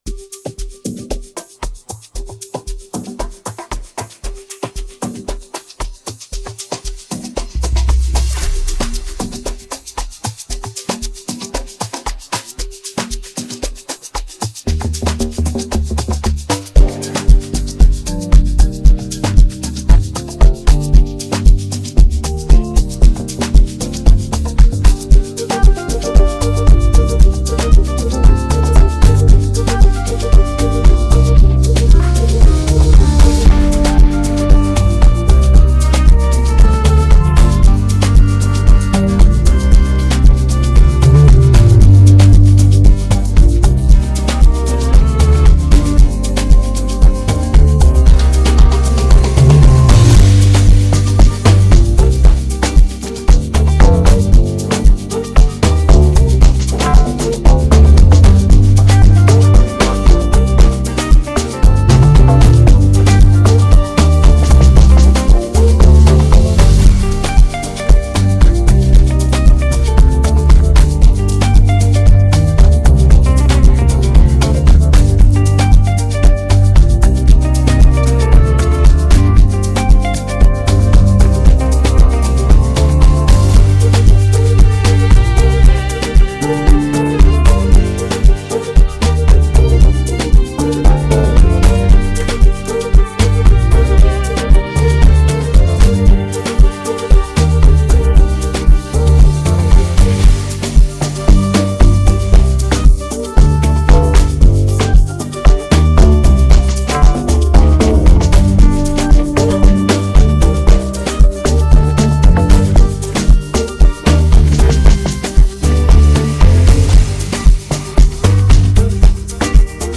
AfrobeatsAmapaino
showcasing an intricate production style